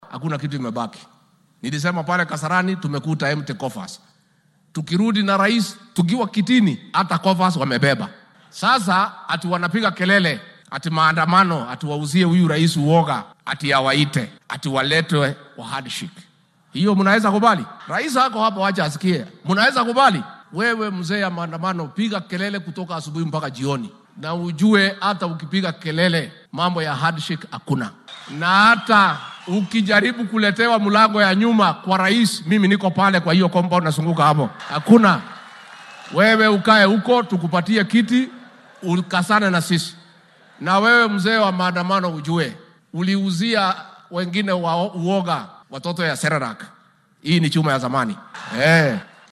Ku xigeenka madaxweynaha dalka Rigathi Gachagua ayaa dhankiisa fagaaraha Kirubia Stadium ee magaalada Chuka ee ismaamulka Tharaka Nithi ka sheegay in marnaba aan heshiis siyaasadeed oo la mid ah midkii sanadkii 2018-kii lala gaari doonin madaxa mucaaradka dalka Raila Odinga.